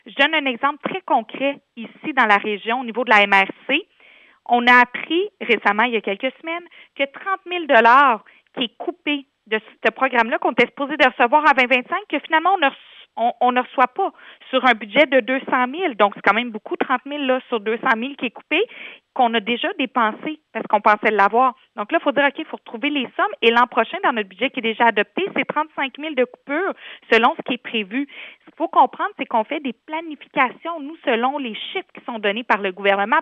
Julie Bourdon, 2ième vice-présidente de l’Union des municipalités du Québec.